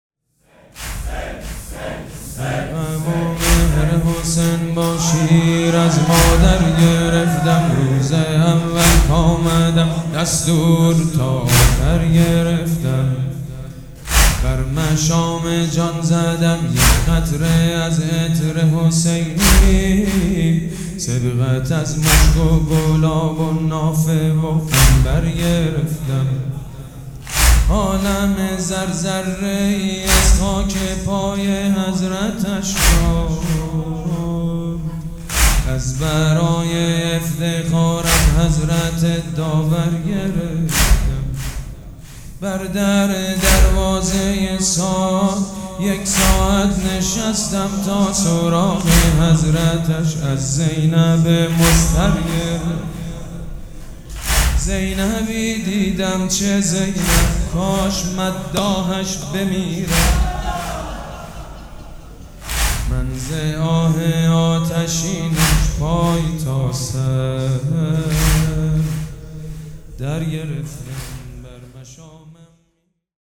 خیمه گاه - شب چهارم محرم - دوطفلان حضرت زینب سلام الله علیها - لیست صوت